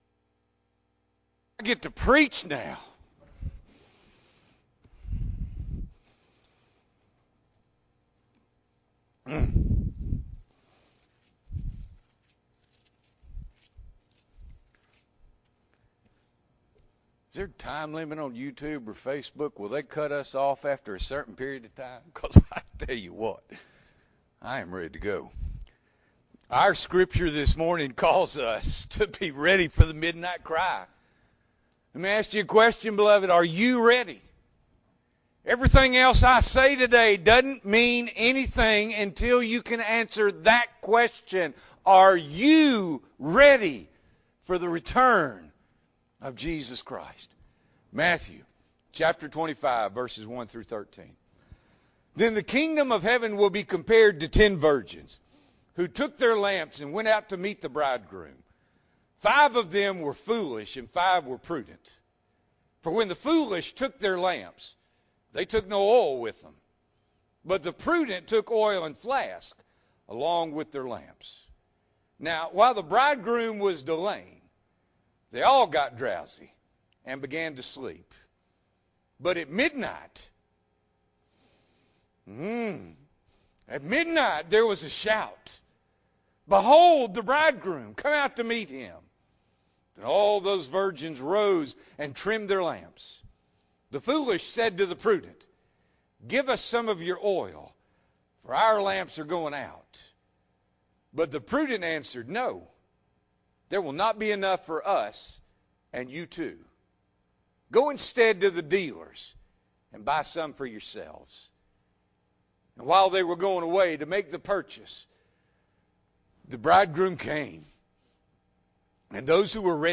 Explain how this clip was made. January 10, 2021 – Morning Worship